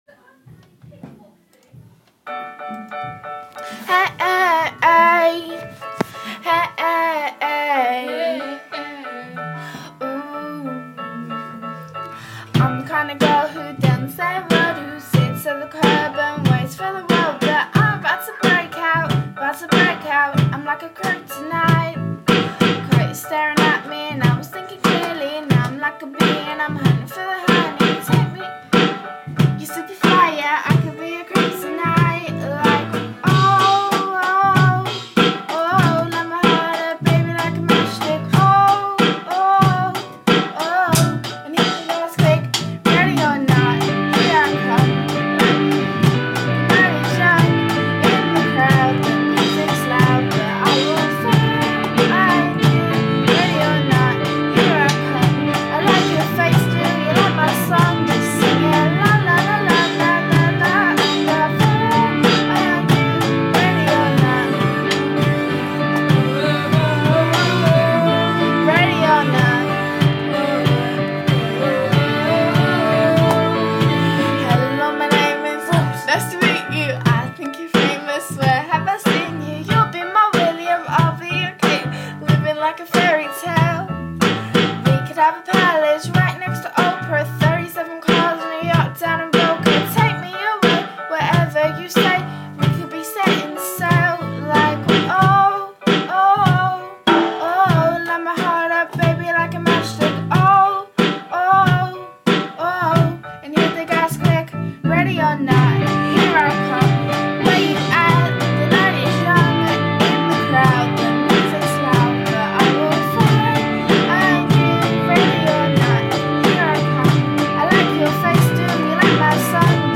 ready or not drums and singing not polished